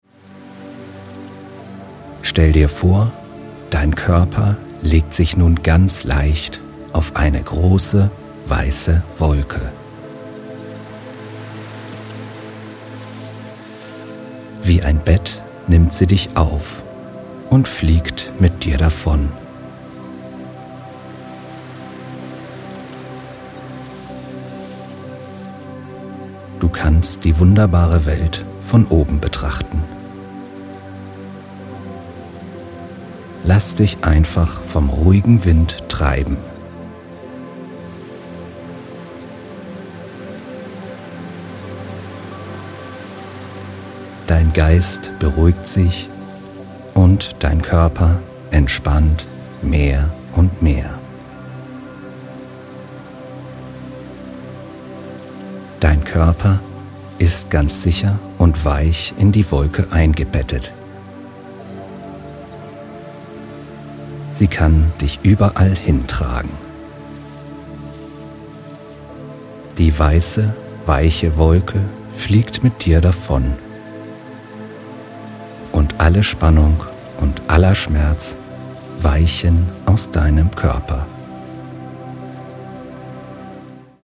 Atemtechniken; Progressive Muskelentspannung; Autogenes Training; entspannende, schmerzlindernde Phantasiereise; regenerierende Entspannungsmusik in 432 HZ
männliche Stimme